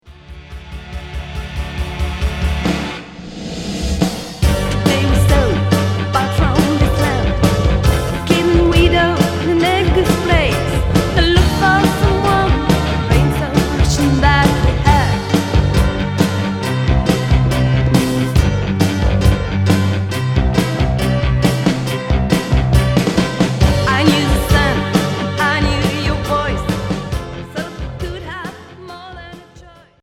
Rock FM